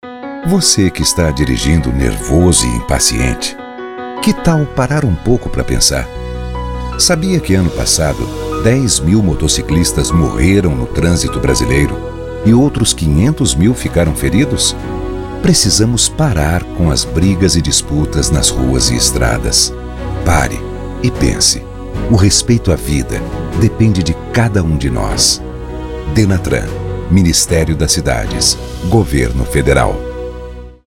Spots de Rádio